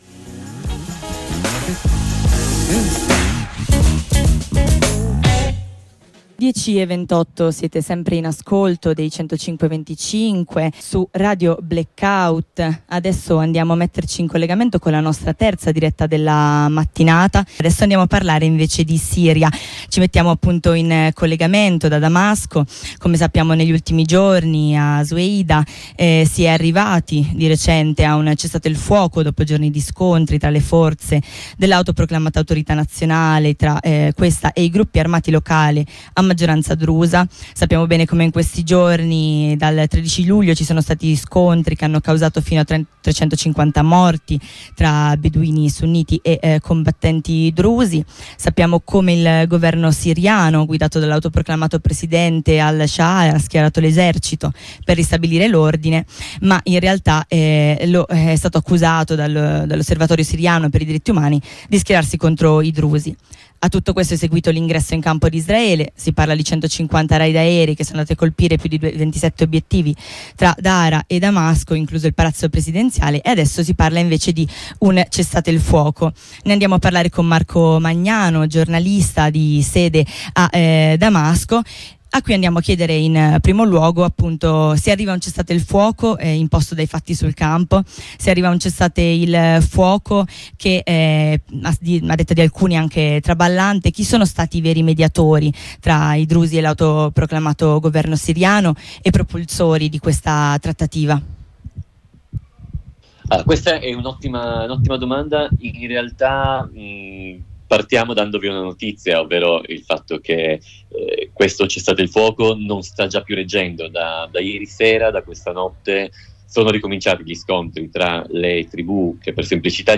in diretta da Damasco